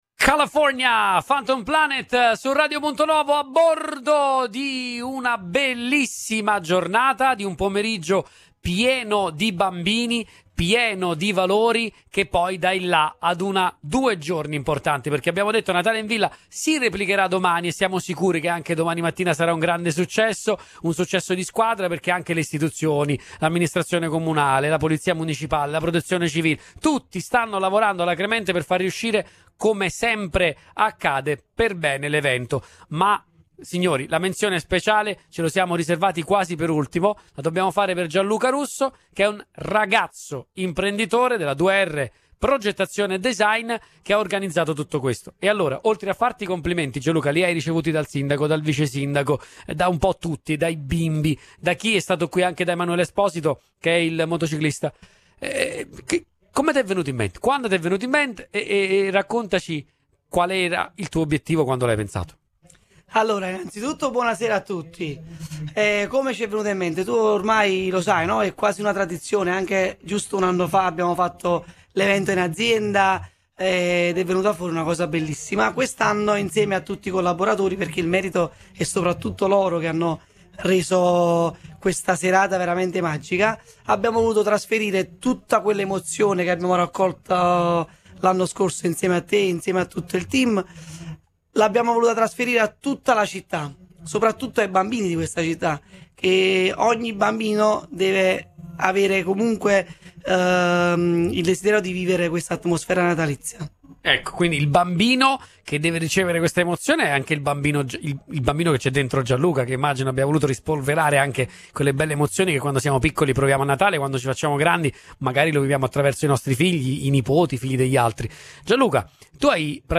A rendere ancora più coinvolgente l’esperienza, oggi abbiamo trasmesso in diretta dall’evento, raccontando emozioni, sorrisi e i tanti desideri natalizi dei più piccoli.